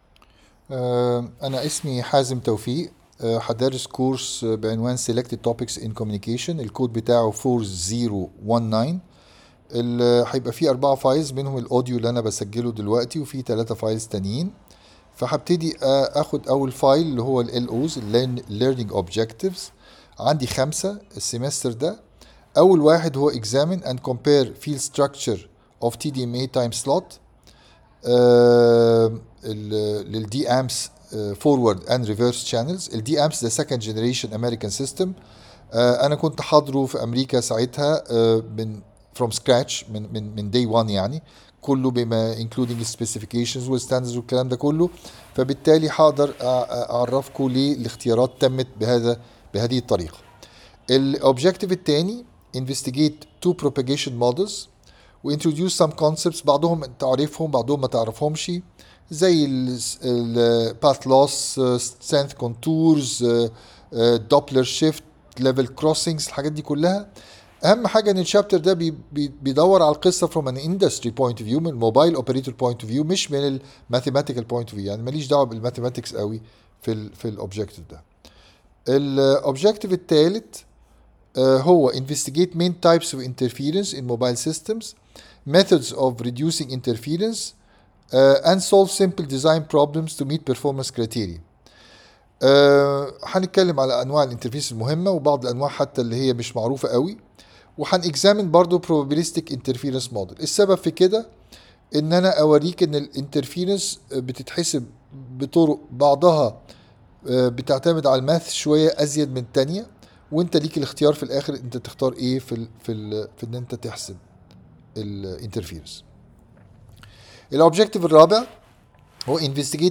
Introductory lectures for Elective Courses of 4th Year (Spring 2026 of Academic Year 2025/2026) are available for access and display